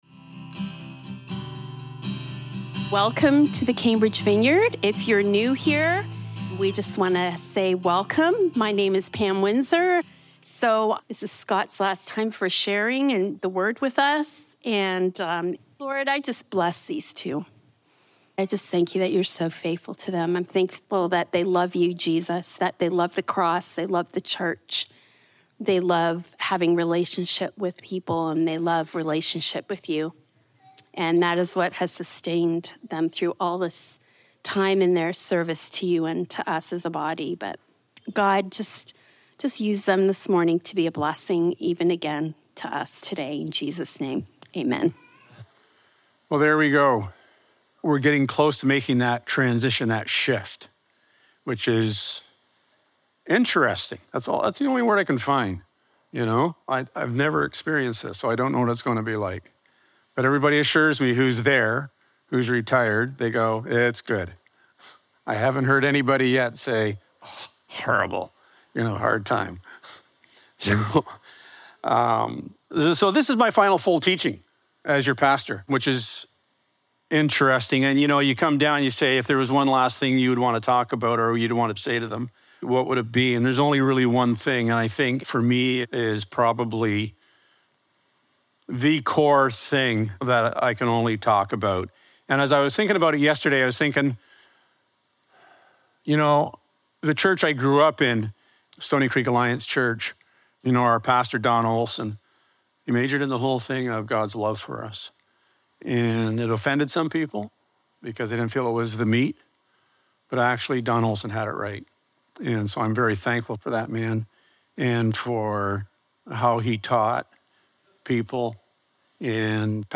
37-40 Service Type: Sunday Morning How you begin will determine how you will finish.